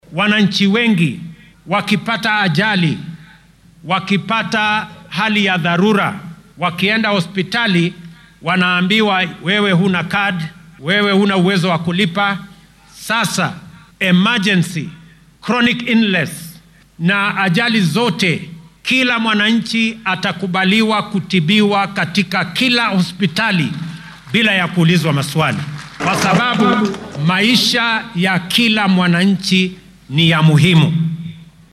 Madaxweynaha dalka William Ruto oo maanta khudbad ka jeedinayay munaasabadda xuska maalinta halyeeyada wadanka ee Mashujaa Day ayaa sheegay in dhammaan kenyaanka ay heli doonaan adeegyo caafimaad oo tayo leh, ay awoodaan sidoo kalena aan lagu kala takoorin.